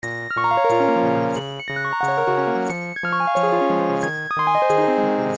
描述：他是我听到的最简单但最甜蜜的hip hop循环之一。
Tag: 180 bpm Hip Hop Loops Synth Loops 918.97 KB wav Key : Unknown